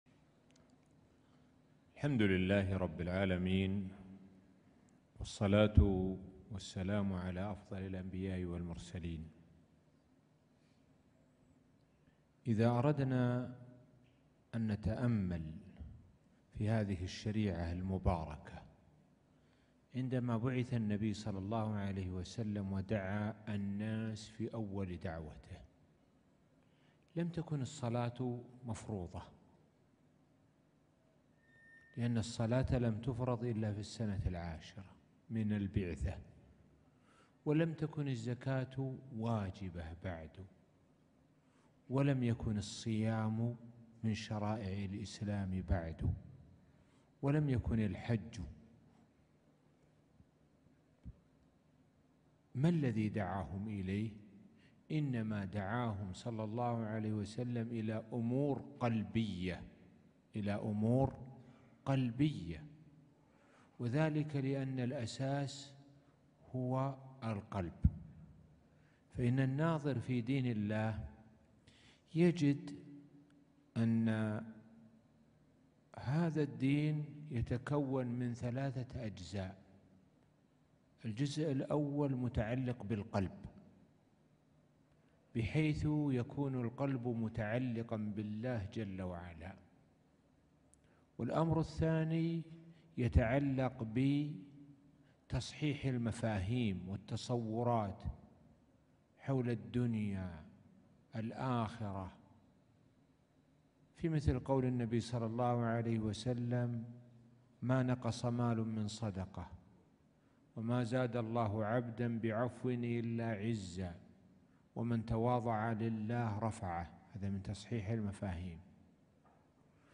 محاضرة قيمة - القلب السليم